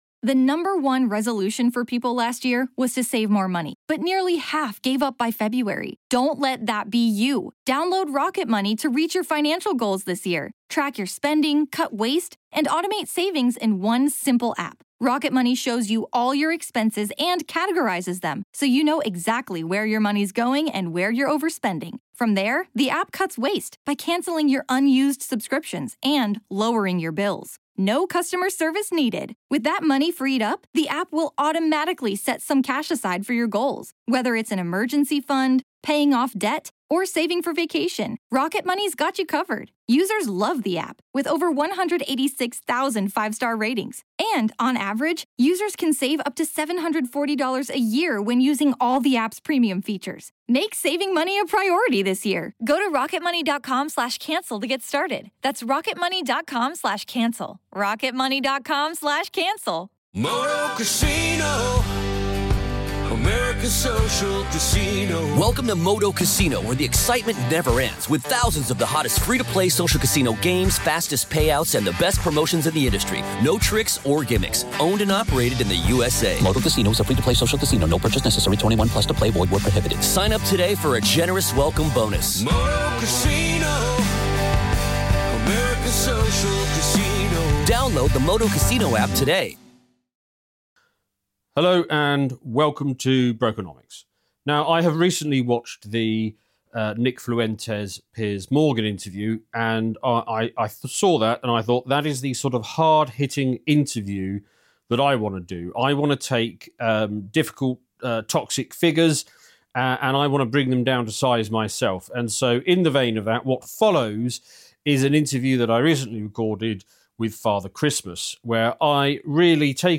FREEMIUM: Brokenomics | The Santa Interview